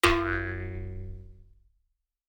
Звуки анимации
Прыжок взлет и удар